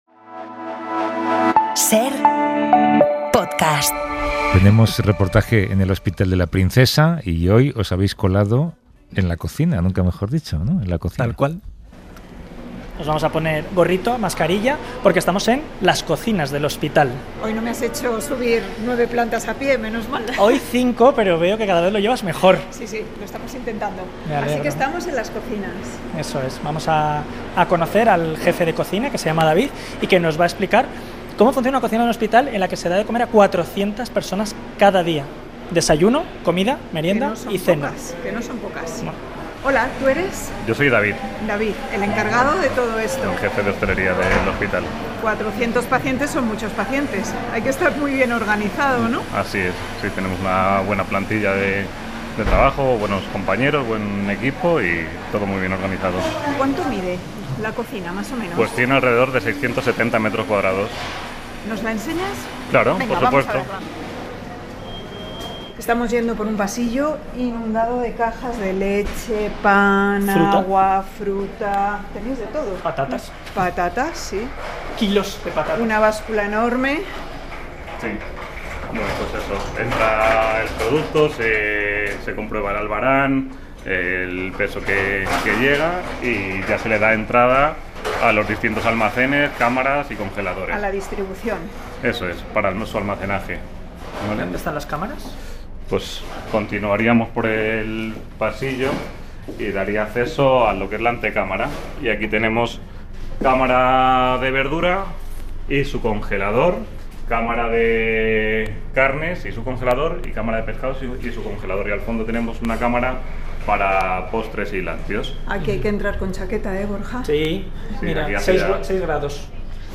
Alimentación hospitalaria, una pieza fundamental en el proceso de recuperación de los pacientes 22:49 SER Podcast Visitamos la cocina del hospital de La Princesa, en Madrid, para conocer de cerca cómo es y cómo está organizado el proceso de alimentación hospitalaria y cuál es el trabajo de la unidad de nutrición y dietética.